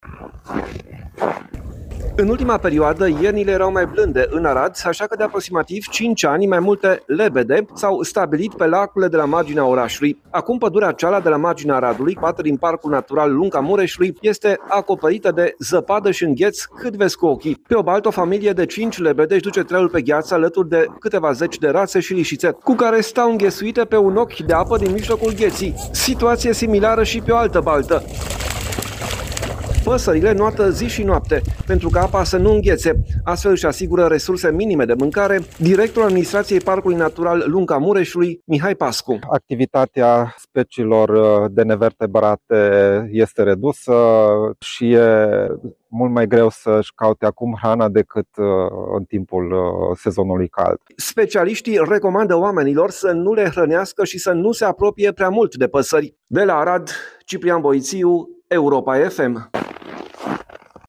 Reportaj: Viața păsărilor sălbatice pe lacurile înghețate din Arad | AUDIO